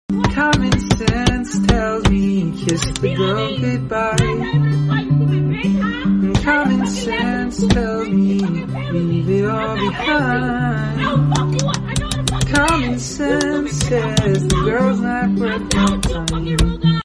mulher revoltada batendo no vidro sound effects free download